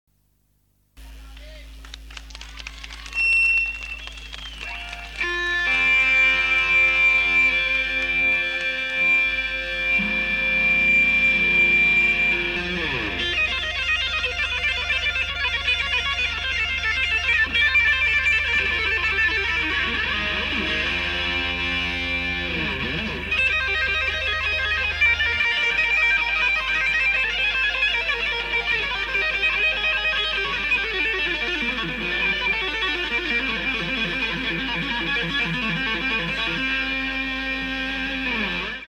Live Guitar Solo
mySolo.mp3